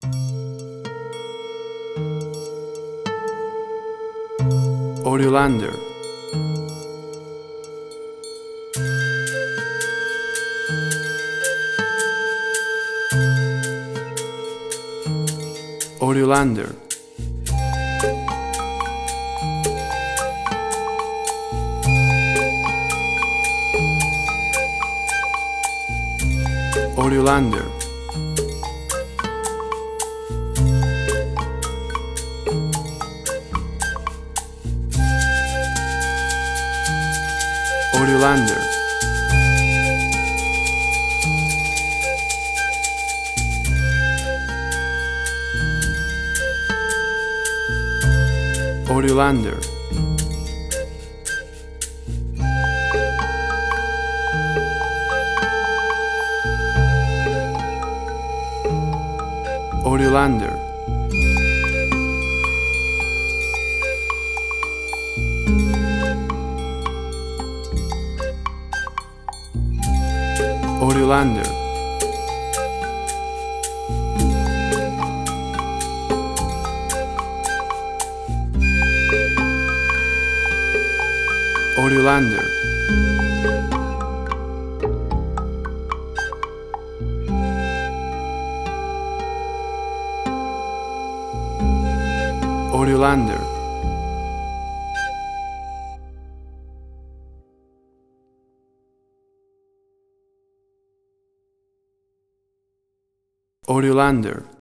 A desertic landscape, stillness and sand.
Tempo (BPM): 110